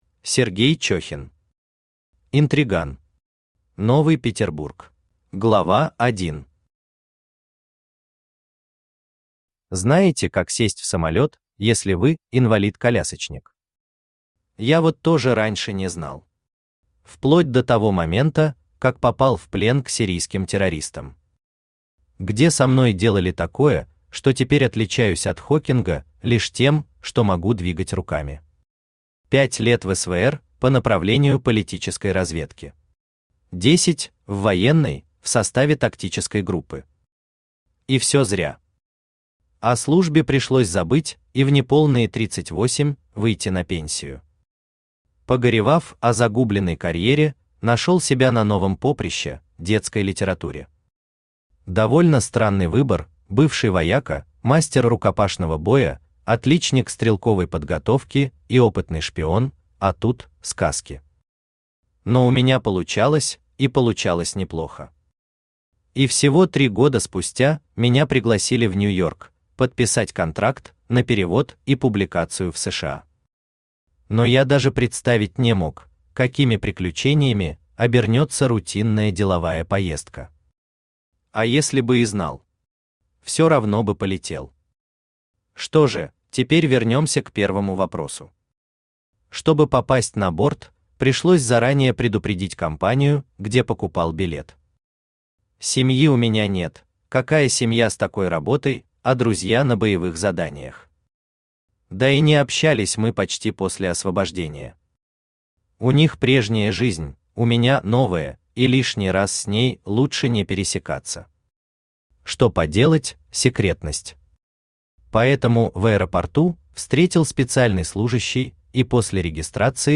Аудиокнига Интриган. Новый Петербург | Библиотека аудиокниг
Новый Петербург Автор Сергей Николаевич Чехин Читает аудиокнигу Авточтец ЛитРес.